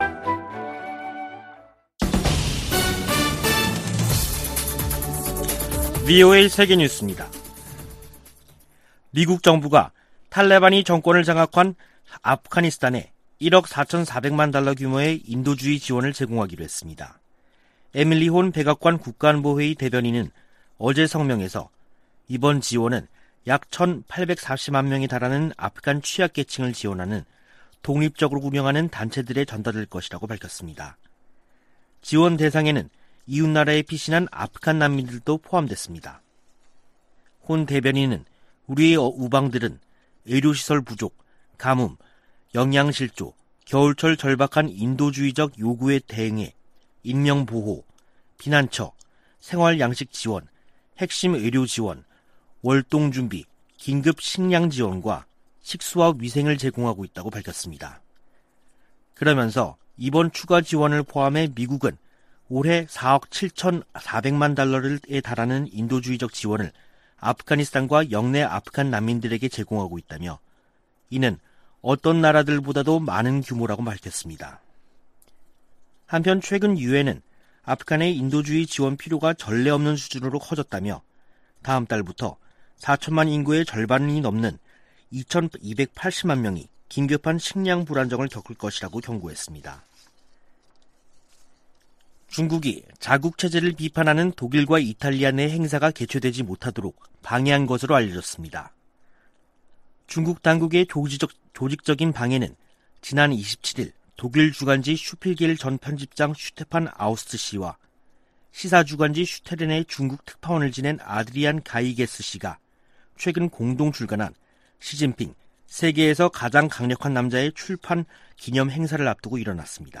VOA 한국어 간판 뉴스 프로그램 '뉴스 투데이', 2021년 10월 29일 3부 방송입니다. 북한 신의주와 중국 단둥간 철도 운행이 다음달 재개될 가능성이 있다고 한국 국가정보원이 밝혔습니다. 유럽연합이 17년 연속 유엔총회 제3위원회에 북한 인권 상황을 규탄하는 결의안을 제출했습니다. 유엔총회 제1위원회에서 북한 핵과 탄도미사일 관련 내용 포함 결의안 3건이 채택됐습니다.